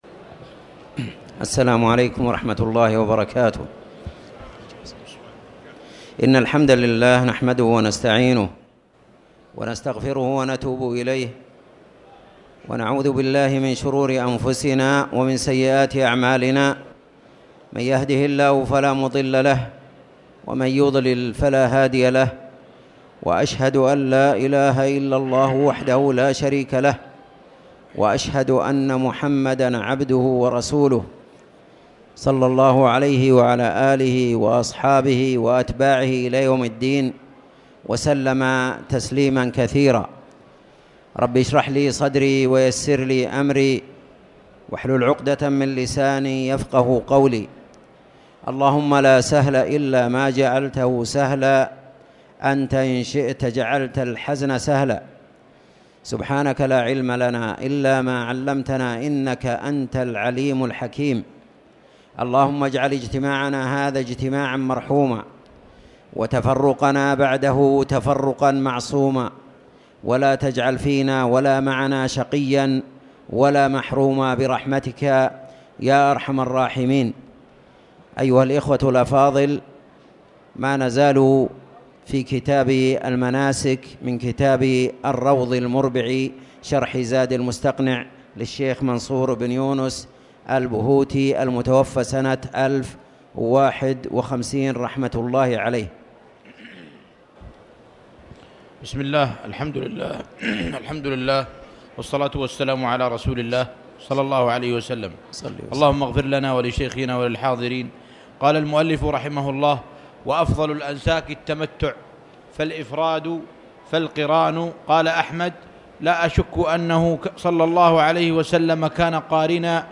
تاريخ النشر ٢٠ رجب ١٤٣٨ هـ المكان: المسجد الحرام الشيخ